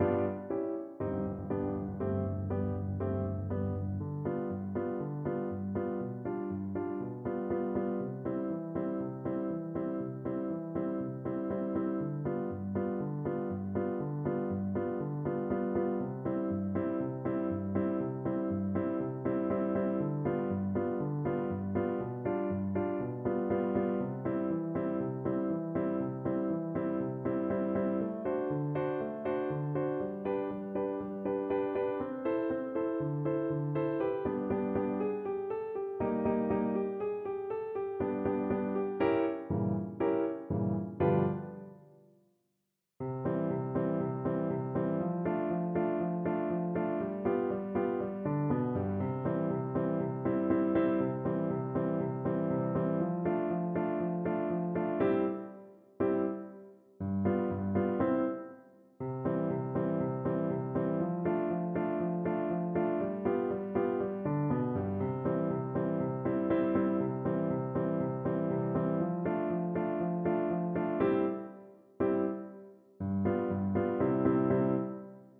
French Horn
2/4 (View more 2/4 Music)
Molto allegro
Arrangement for French Horn and Piano
C major (Sounding Pitch) G major (French Horn in F) (View more C major Music for French Horn )
Classical (View more Classical French Horn Music)
Polkas for French Horn